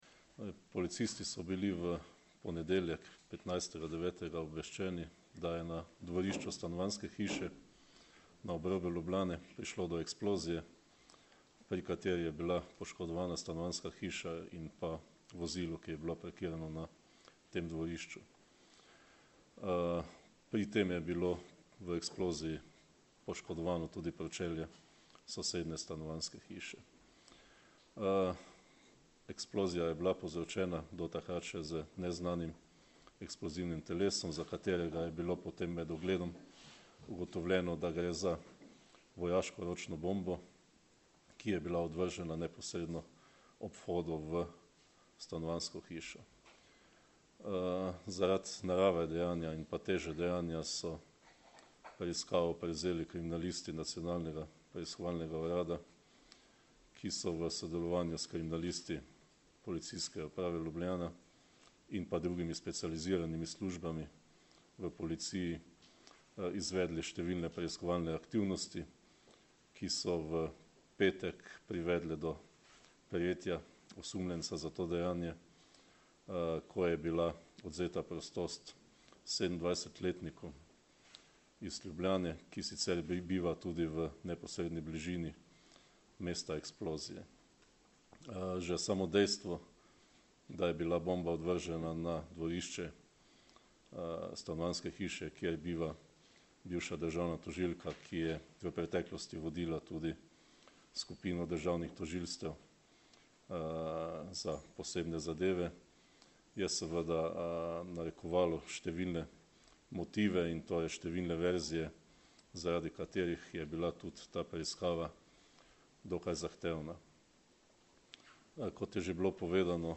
Kot je v današnji izjavi za javnost povedal Marjan Fank, direktor Uprave kriminalistične policije, so na kraju kaznivega dejanja preiskovalci Nacionalnega preiskovalnega urada, kriminalisti in policisti Policijske uprave Ljubljana, bombni tehniki Specialne enote in strokovnjaki Nacionalnega forenzičnega laboratorija skupaj opravili ogled.
Zvočni zapis izjave Marjana Fanka (mp3)
MarjanFank.mp3